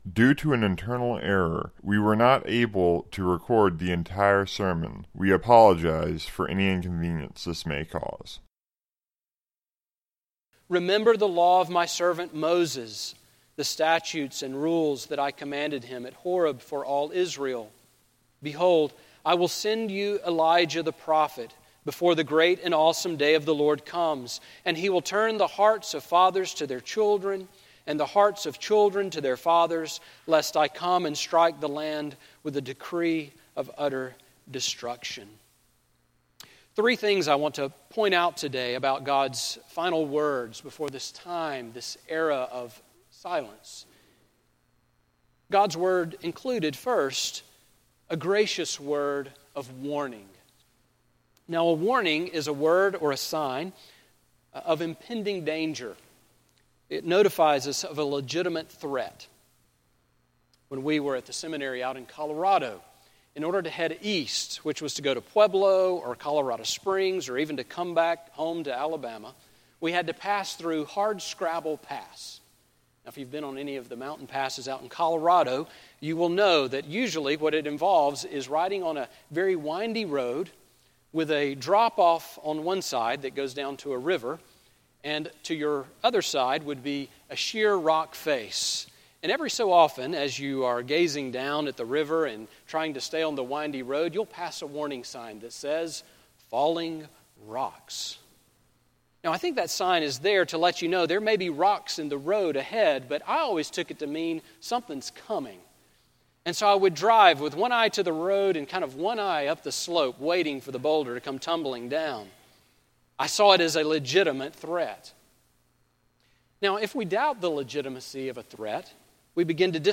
Sermon on Malachi 4 from December 14